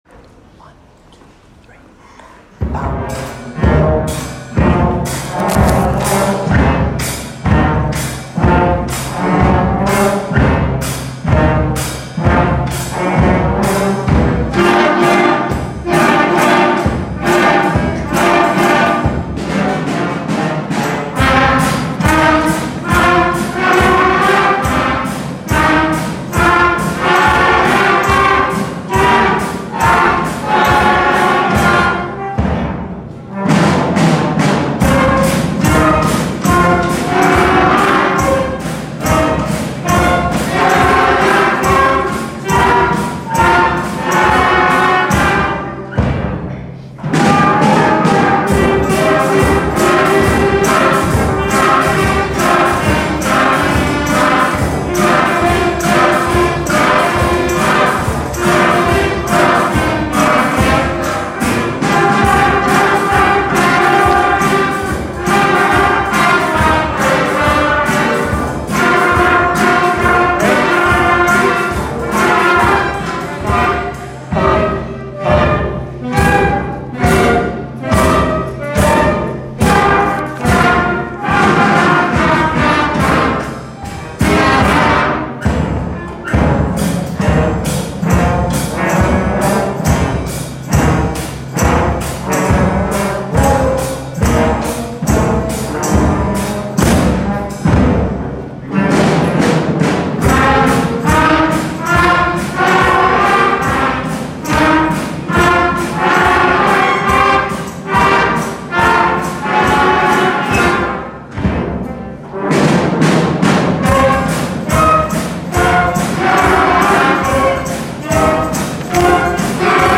I was fortunate enough to be able to conduct 100 children recently in a performance of Creepy Crawly.